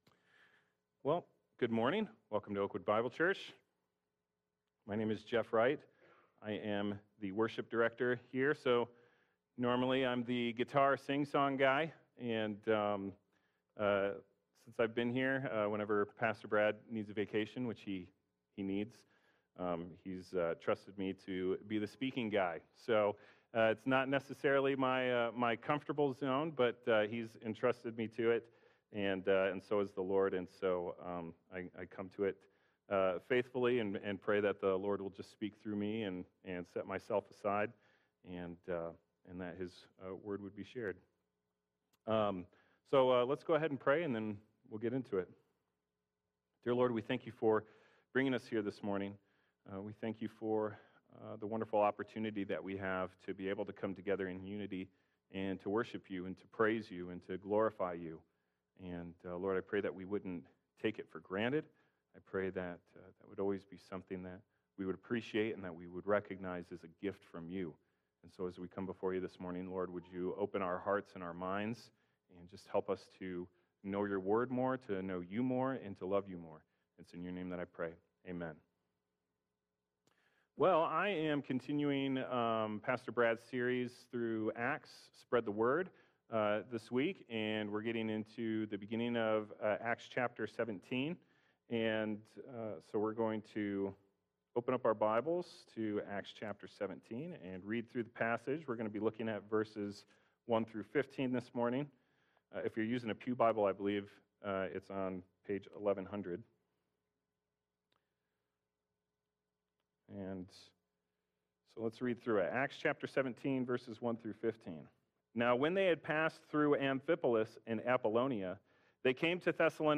Passage: Acts 17:1-15 Service Type: Worship Service